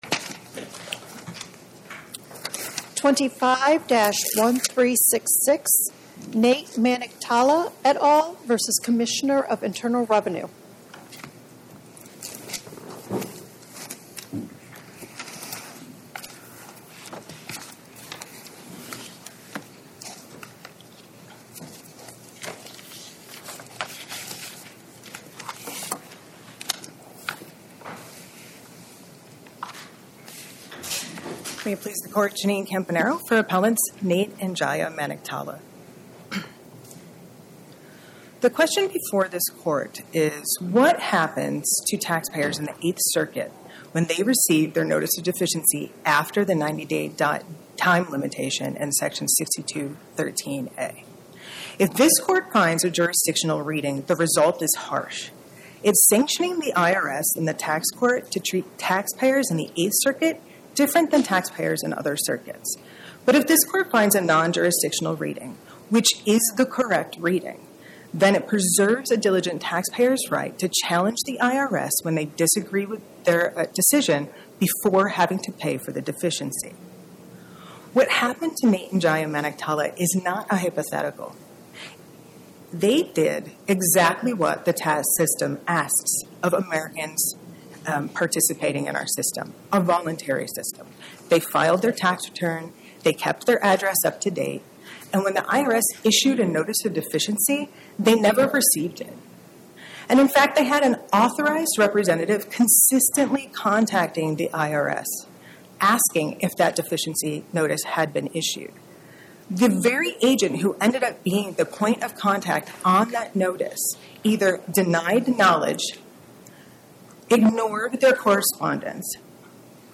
Oral argument argued before the Eighth Circuit U.S. Court of Appeals on or about 12/17/2025